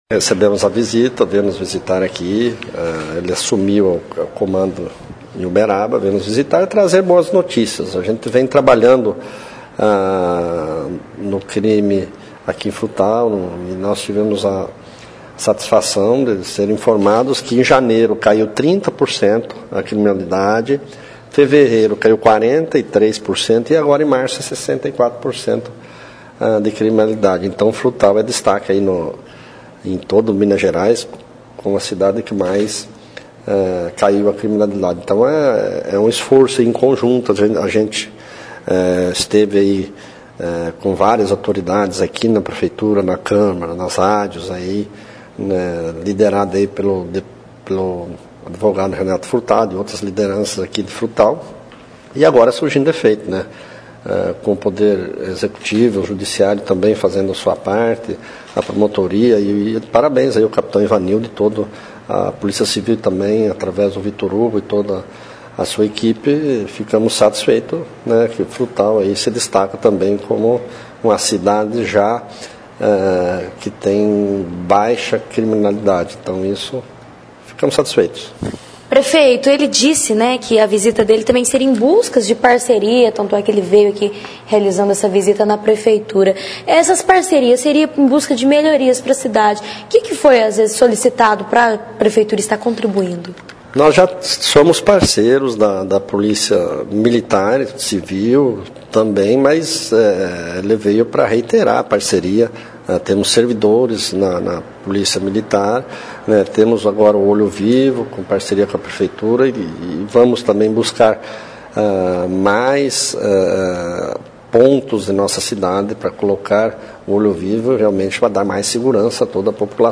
O Prefeito Mauri José Alves, fala sobre esse encontro com o Comandante da Polícia Militar.